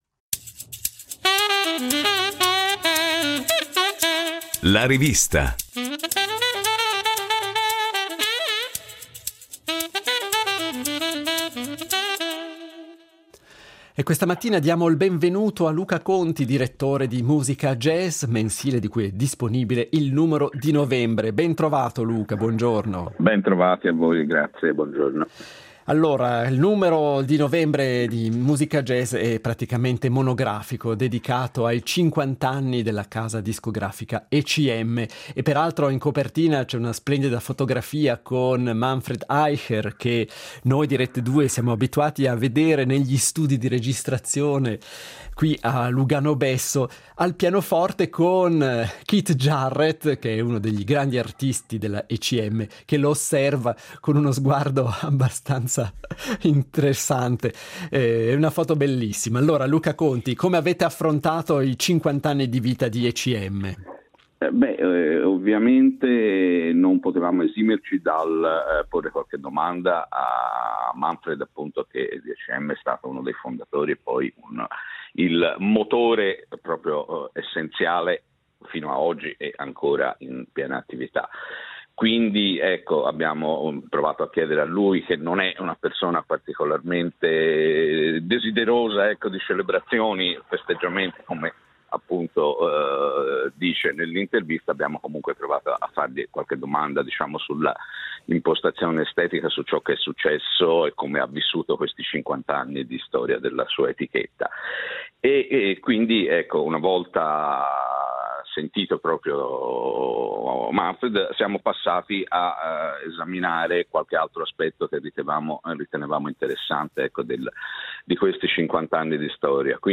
Musica Jazz